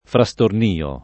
frastornio [ fra S torn & o ] s. m.